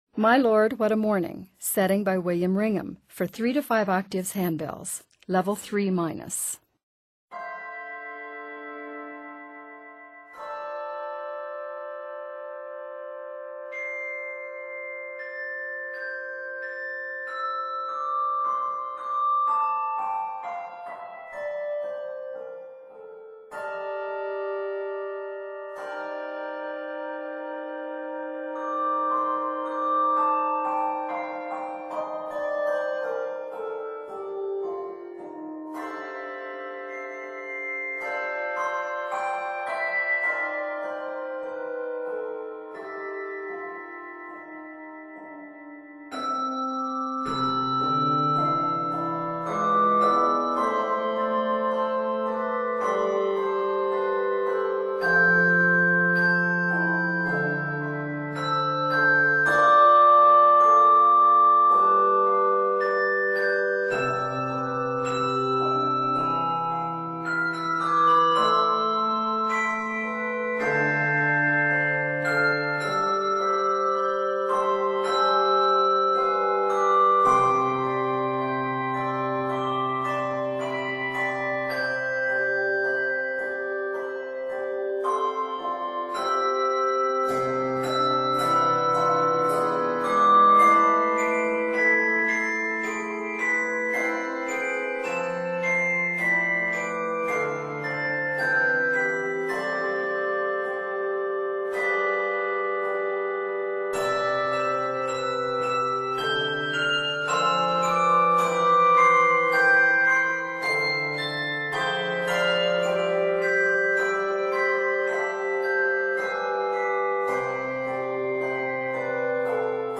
Octaves: 3-5